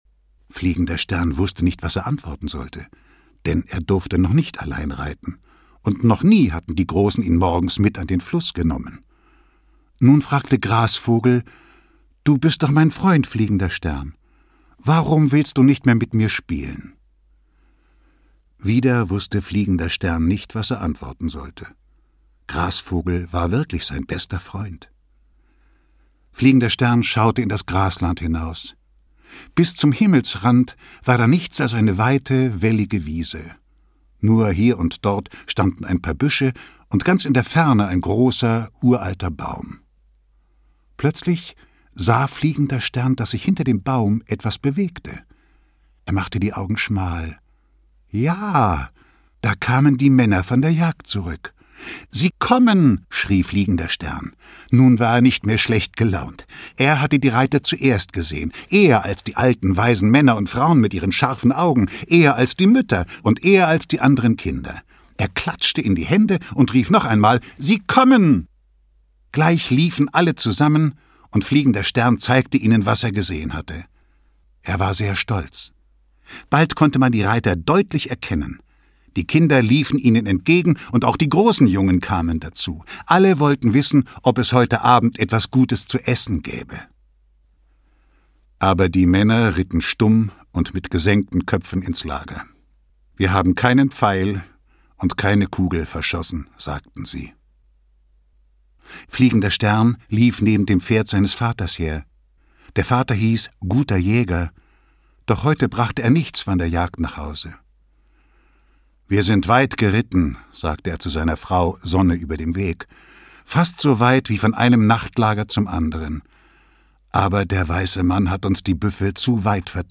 Fliegender Stern - Ursula Wölfel - Hörbuch